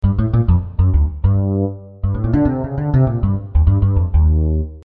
大象低音炮
描述：无支架贝斯轨道
标签： 贝司 B小调 无品系
声道立体声